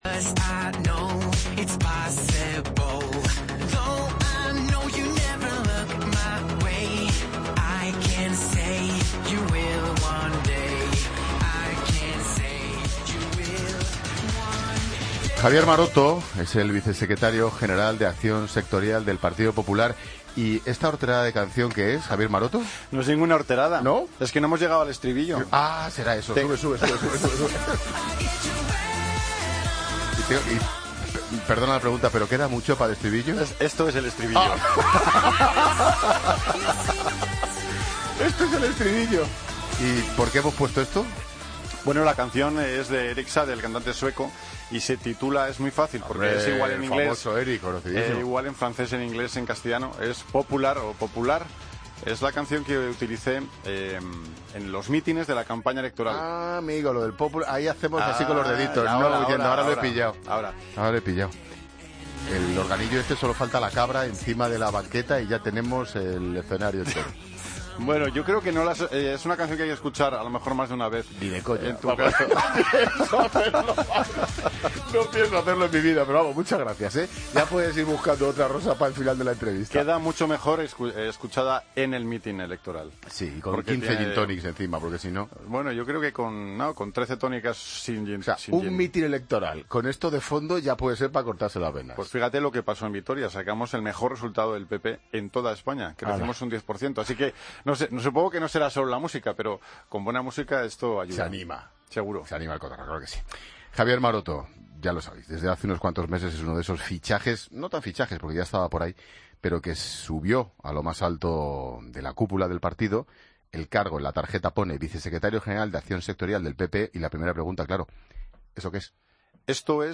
Escucha la entrevista de Javier Maroto en La Tarde con Ángel Expósito